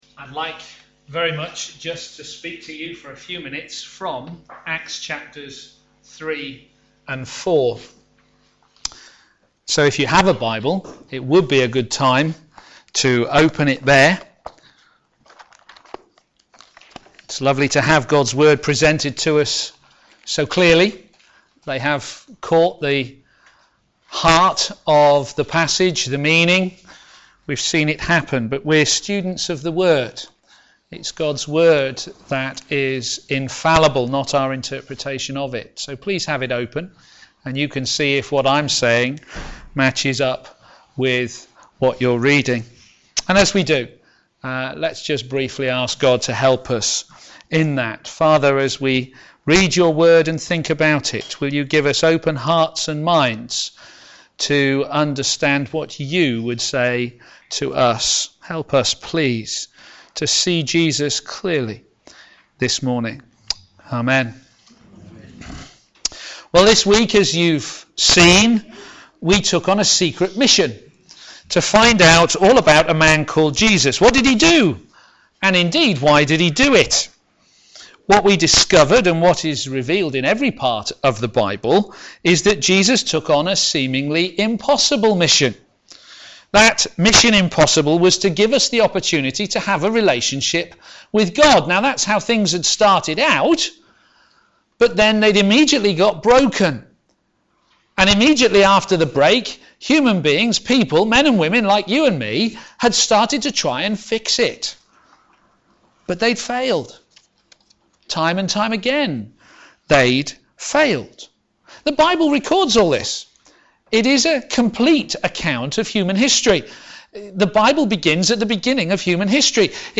Media for a.m. Service
Theme: Jesus our Saviour Sermon